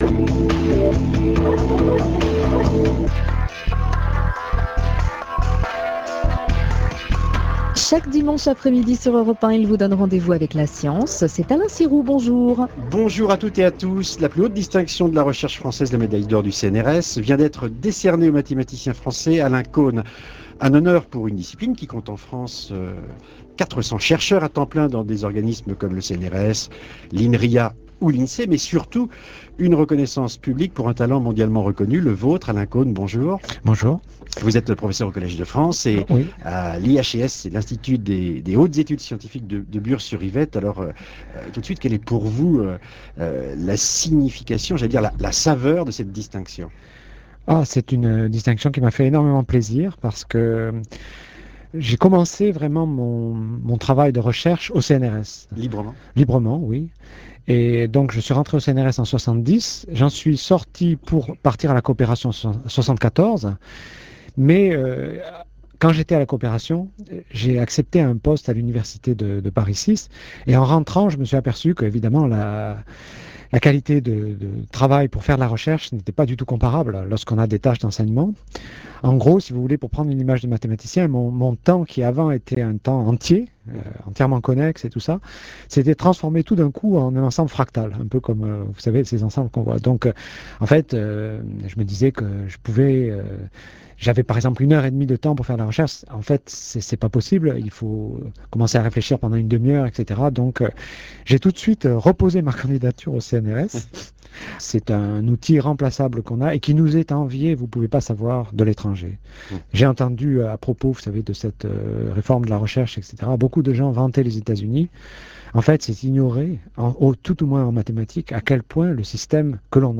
L'explosion des mathématiques (brochure éditée par la SMF) Image des mathématiques (site mis à jour par le CNRS) Mathematical Subject Classification (extrait du site de l'AMS) Interview du mathématicien Alain Connes (médaille d'or du CNRS en 2004)